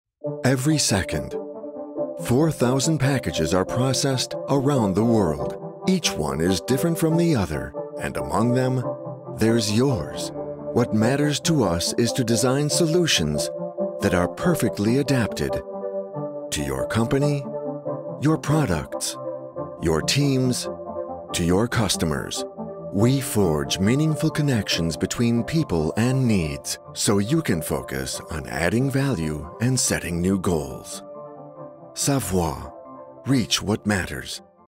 Naturelle, Polyvalente, Chaude, Mature, Corporative
E-learning